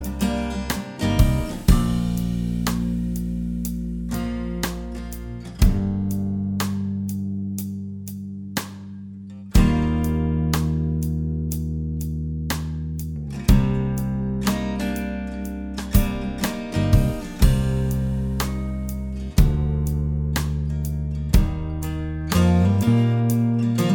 Duet Version Rock 5:15 Buy £1.50